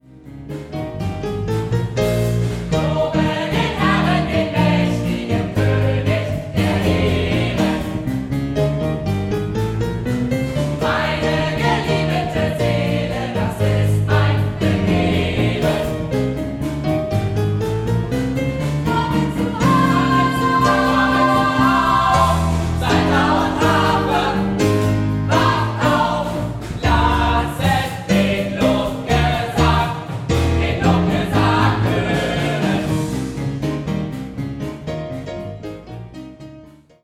Gospelchoral
• SATB + Piano